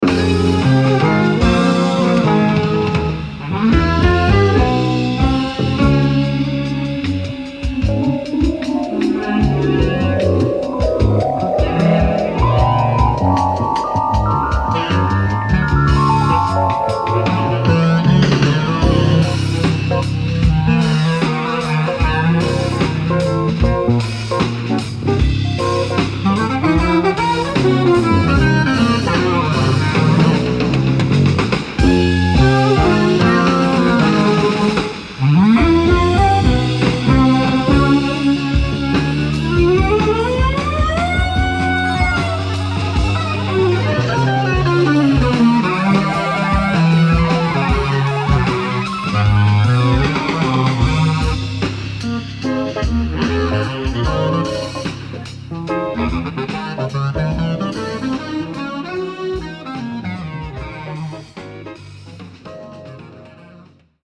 Piano, Keyboards, Percussion
Electric Bass, Percussion
Drums, Congas